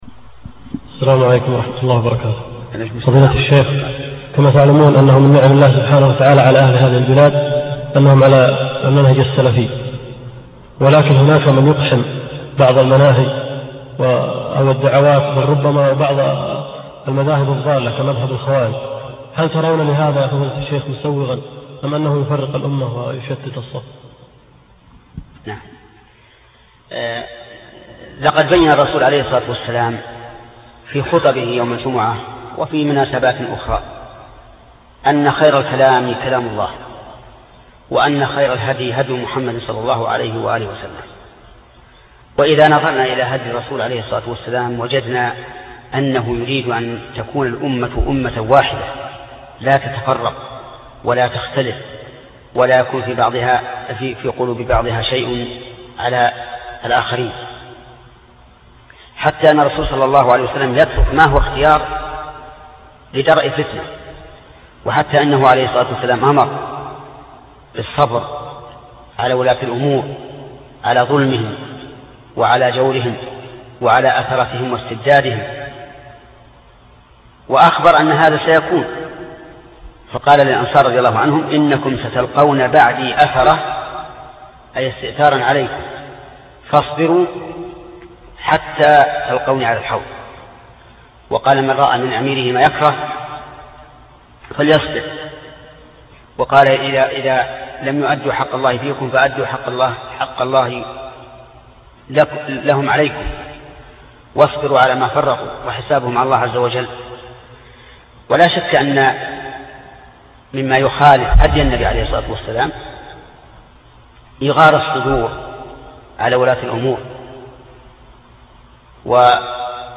موقع النهج الواضح يسهر على نقل الدروس العلمية عبر إذاعته، وكذلك نشر دروس وشروحات ومحاضرات ومقالات كبار العلماء، كما ينظم لقاءات مفتوحة مع العلماء للإجابة على أسئلة المستمعين والزوار ومن ثم نشرها في قسم الفتاوى بالموقع.